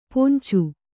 ♪ poncu